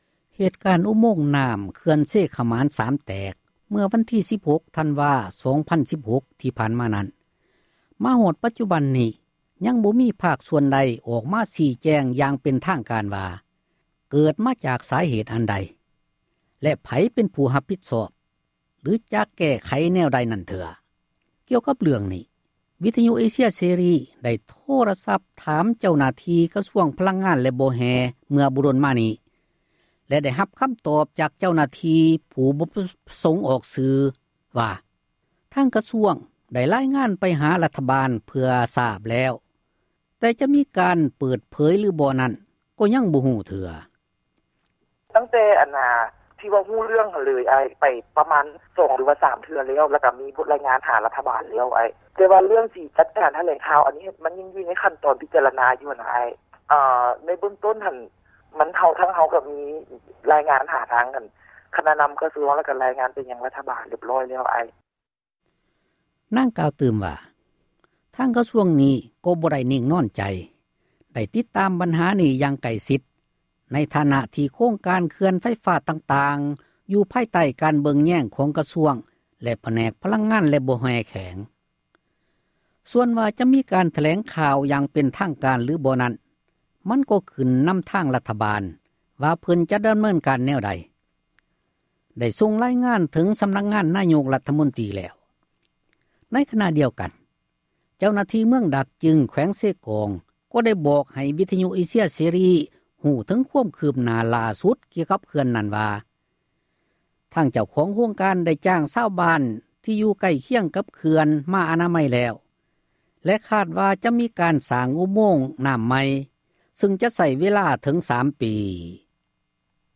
ກ່ຽວກັບເຣື້ອງນີ້, ວິທຍຸ ເອເຊັຽເສຣີ ໄດ້ ໂທຣະສັບ ຖາມໄປຍັງ ເຈົ້າໜ້າທີ່ ກະຊວງ ພລັງງານ ແລະບໍ່ແຮ່ ເມື່ອບໍ່ດົນມານີ້ ແລະ ໄດ້ຮັບຄໍາ ຕອບ ຈາກ ເຈົ້າໜ້າທີ່ ຜູ້ບໍ່ປະສົງ ອອກຊື່ວ່າ ທາງກະຊວງ ໄດ້ຣາຍງານ ໄປຫາ ຣັຖບານ ເພື່ອໃຫ້ ຮັບຊາບແລ້ວ, ແຕ່ຈະມີການ ເປີດເຜີຍ ຫລືບໍ່ນັ້ນ ກໍ່ຍັງບໍ່ ຮູ້ເທື່ອ.